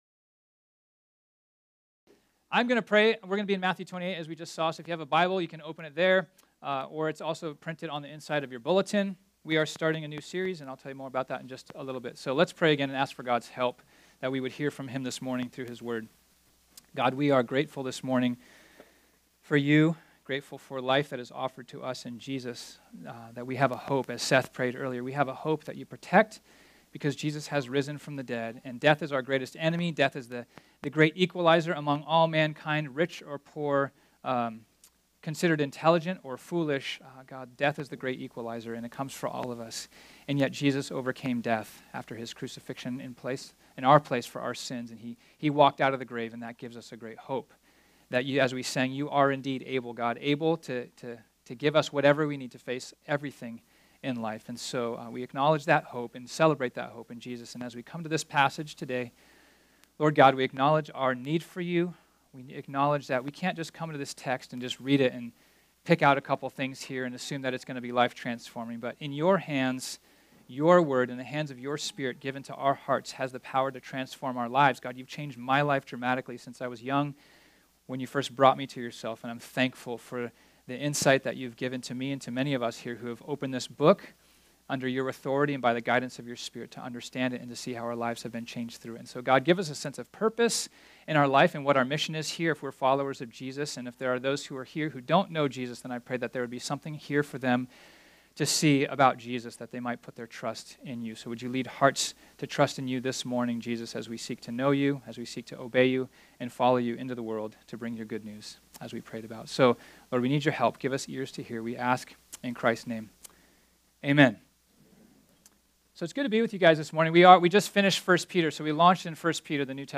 This sermon was originally preached on Sunday, May 6, 2018.